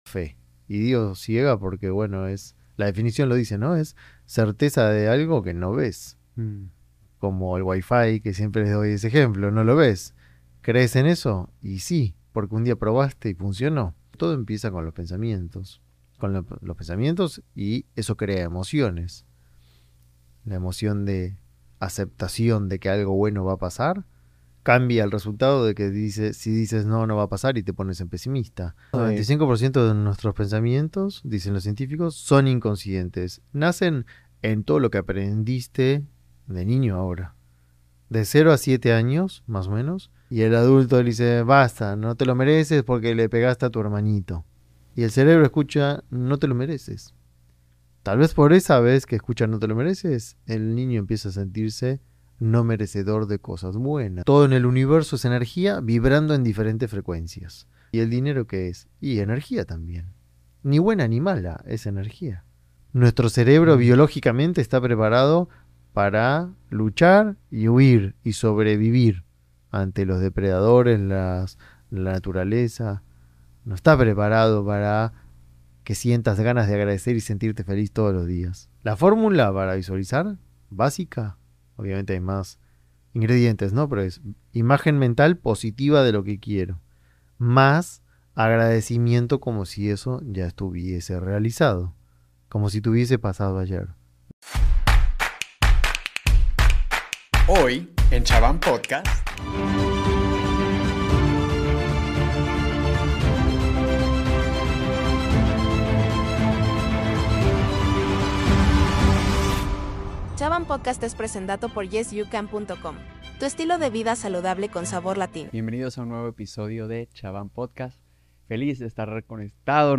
Estoy muy emocionado de invitarlos a un nuevo episodio de Chabán Podcast, donde siempre buscamos compartir conversaciones inspiradoras que nos ayuden a crecer en todas las áreas de nuestra vida.